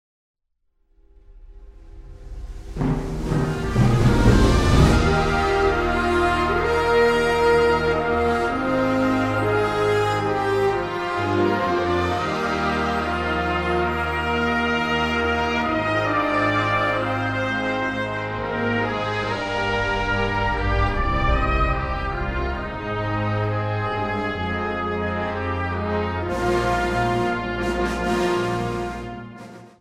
Categoría Banda sinfónica/brass band
Instrumentación/orquestación Ha (banda de música)